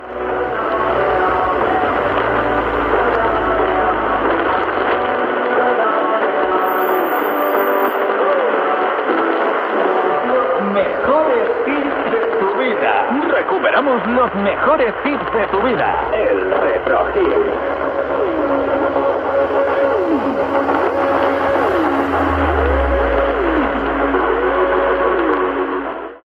Identificació del programa
Qualitat de so defectuosa.